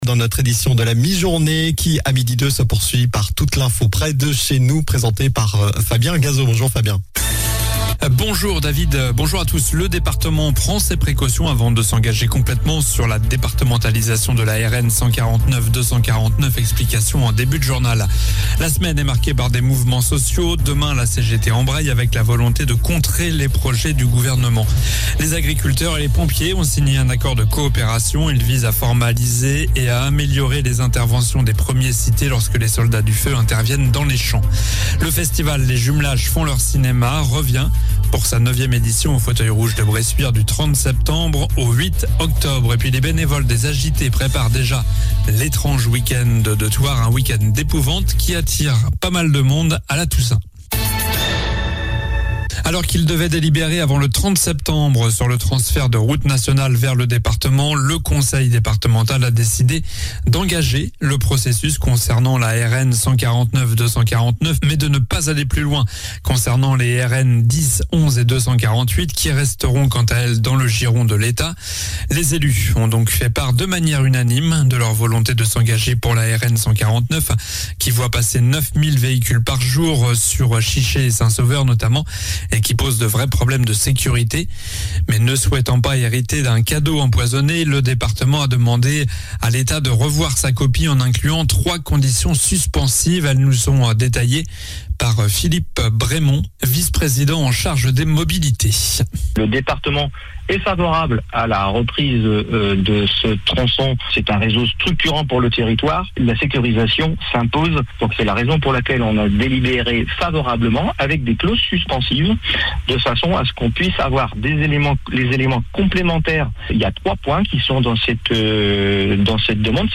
Journal du mercredi 28 septembre (midi)